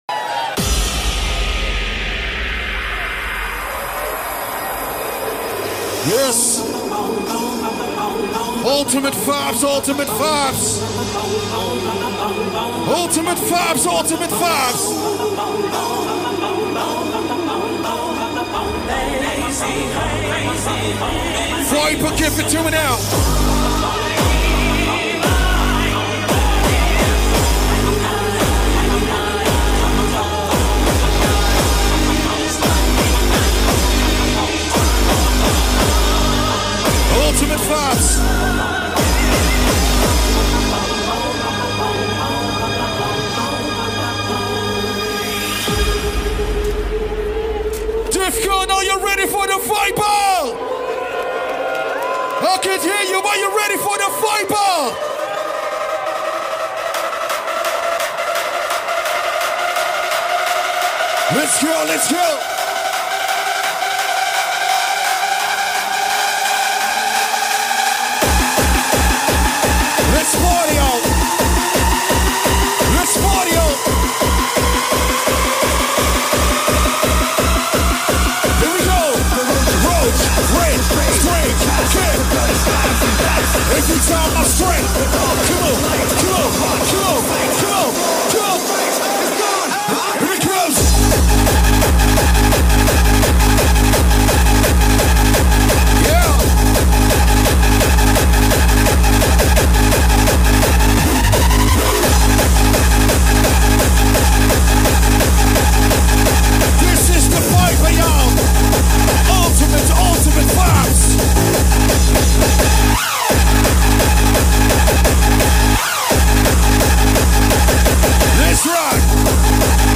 liveset